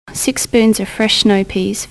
"the speech accent archive"に登録されているネイティブスピーカー、日本人の、それぞれ "Six spoons of fresh snow peas"の部分の音声の波形を見ていきます。
１．【ネイティブスピーカーのS,SHの特徴】 S, SHの音が大きく、発音時間も長い